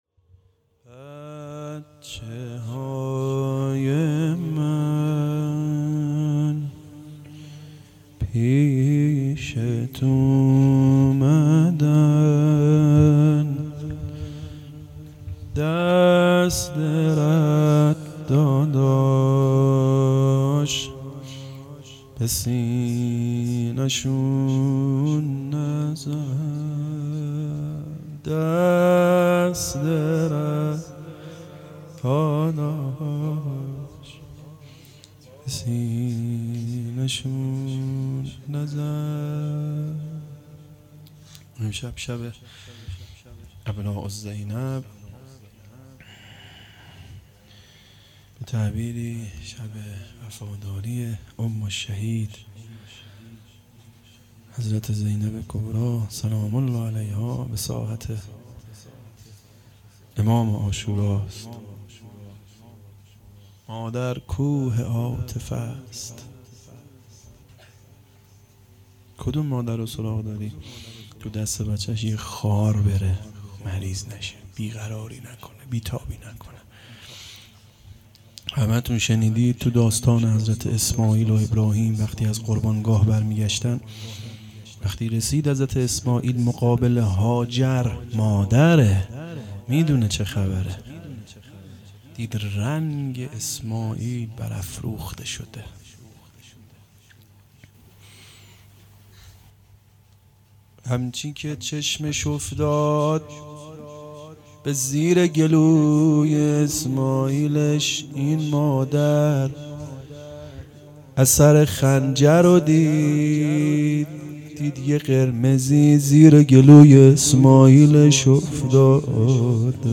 هيأت یاس علقمه سلام الله علیها